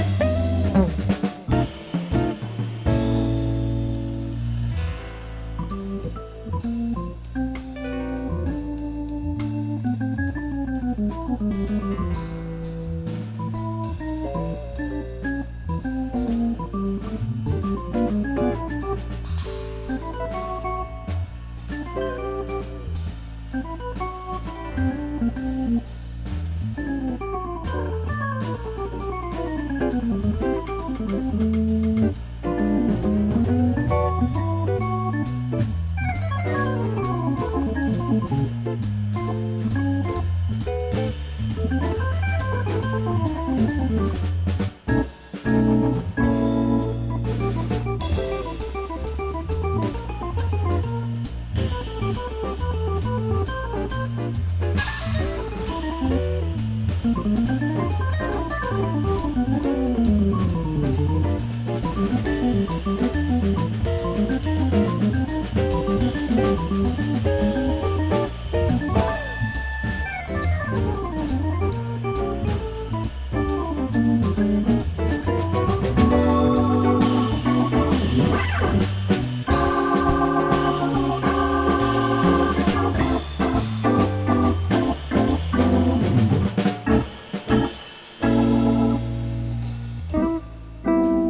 Simple, dépouillé, efficace.
à la guitare